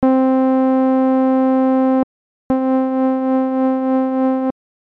1蝗樒岼縺ｯLFO辟｡縺励ｮ髻ｳ濶ｲ縲2蝗樒岼縺ｯLFO譛峨ｊ縺ｮ髻ｳ濶ｲ縺ｫ縺ｪ繧翫∪縺吶LFO繧誕mp縺ｫ縺九¢繧九→縲√が繧ｷ繝ｬ繝ｼ繧ｿ繝ｼ1&2縺ｮ髻ｳ驥上′謠ｺ繧後髻ｳ縺ｮ螟ｧ縺阪＆縺悟､牙喧縺励∪縺吶